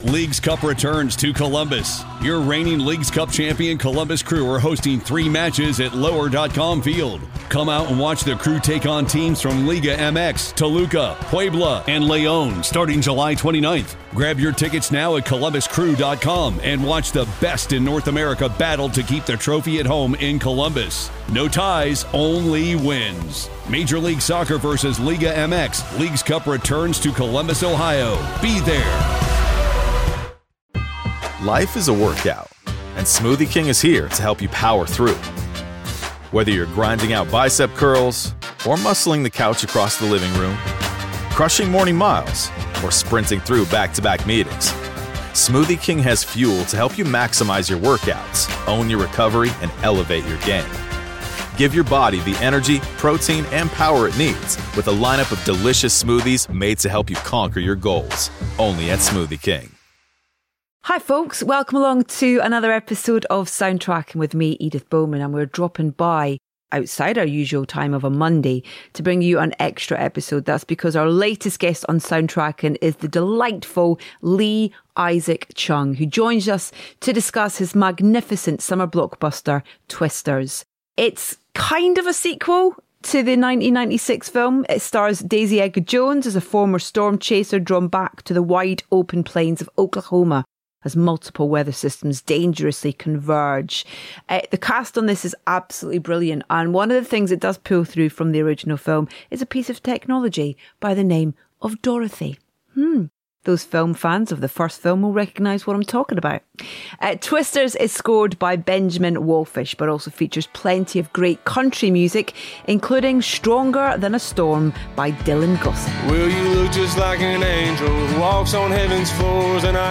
Our latest guest on Soundtracking is the delightful Lee Isaac Chung, who joins us to discuss his magnificent summer blockbuster, Twisters.